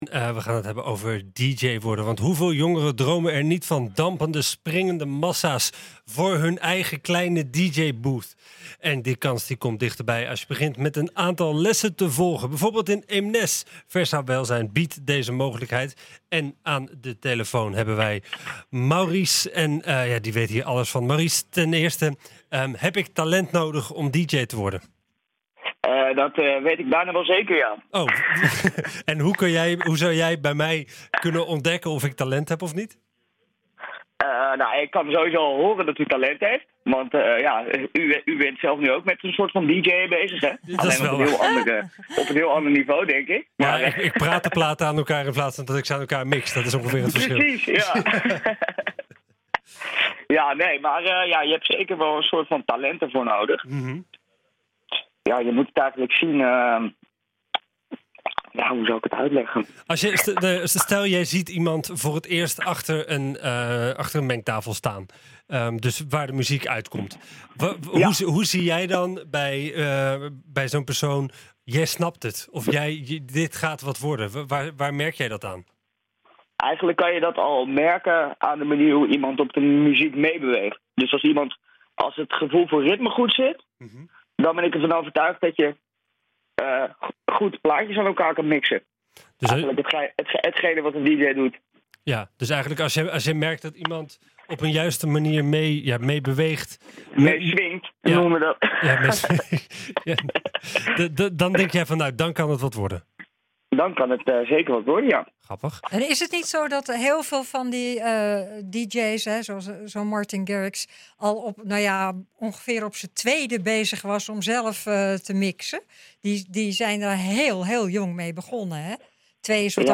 Aan de telefoon